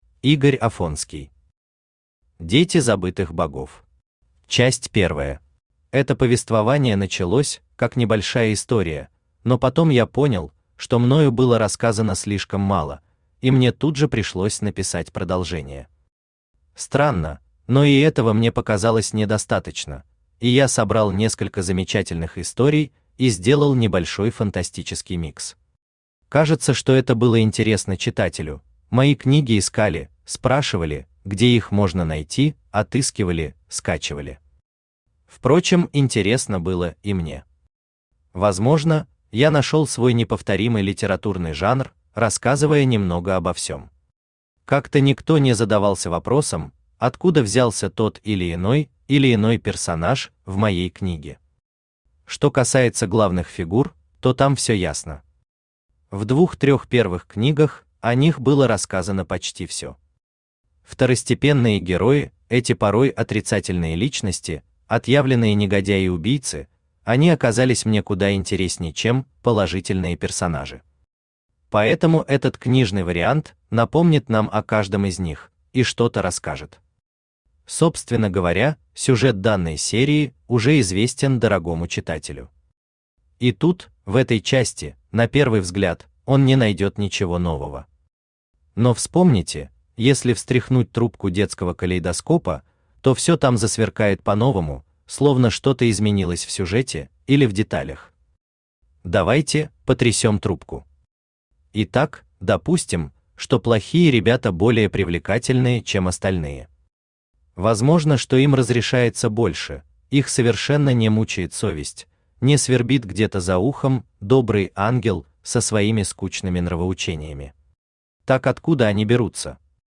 Аудиокнига Дети забытых богов. Часть первая | Библиотека аудиокниг
Часть первая Автор Игорь Олегович Афонский Читает аудиокнигу Авточтец ЛитРес.